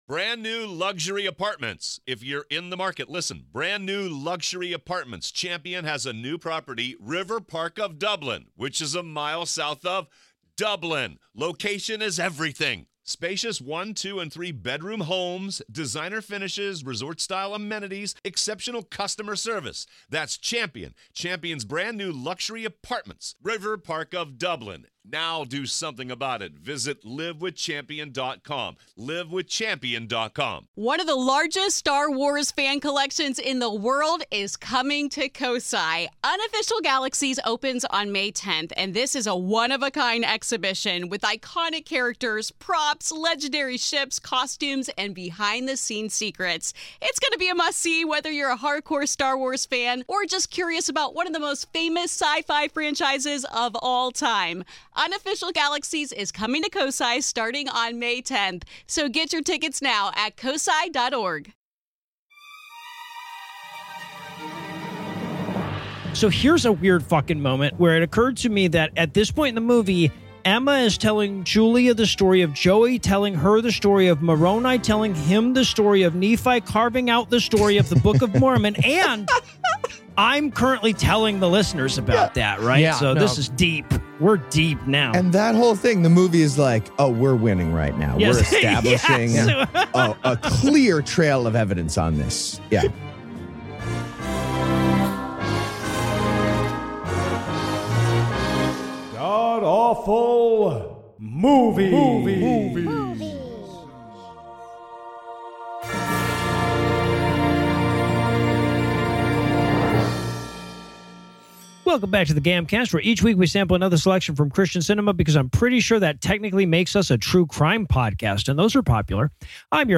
This week, the guys team up for an atheist review of Emma Smith: My Story. It's the story of how you're actually allowed to pretend whatever history you want.